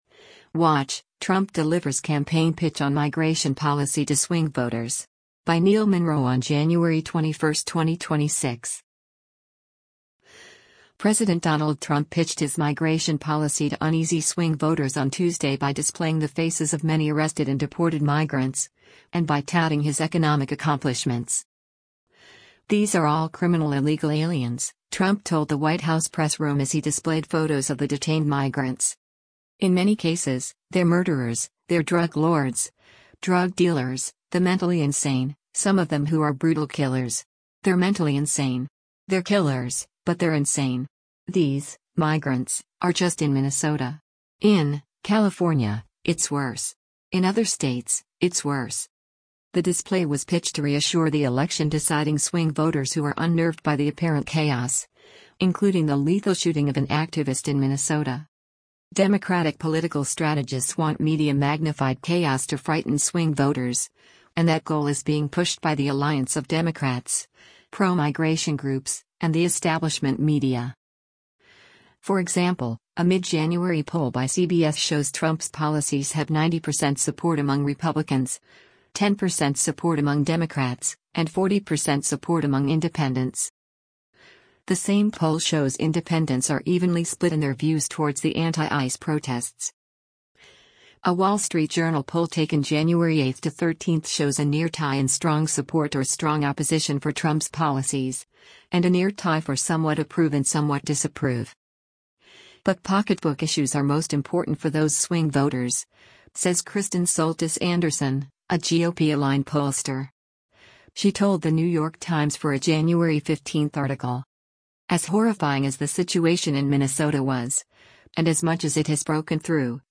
US President Donald Trump speaks during a briefing in the Brady Briefing Room of the White House in Washington, DC, on January 20, 2026.